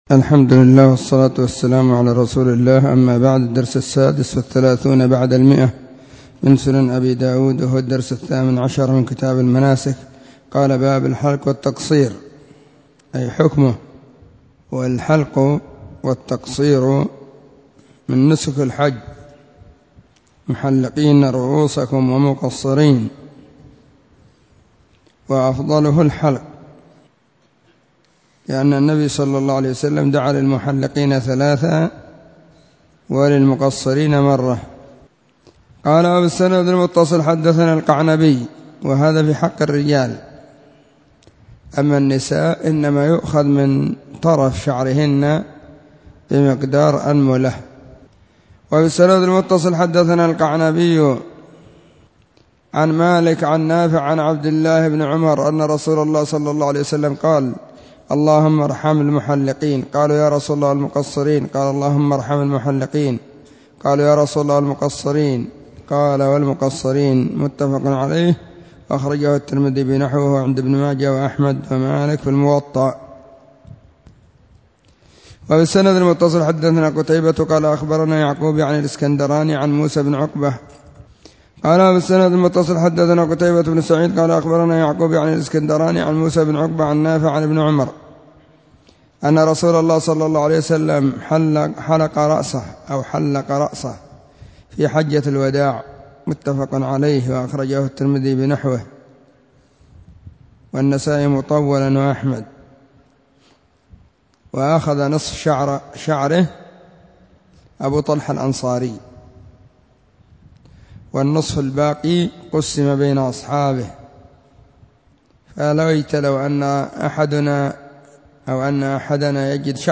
🕐 [بعد صلاة العصر في كل يوم الجمعة والسبت]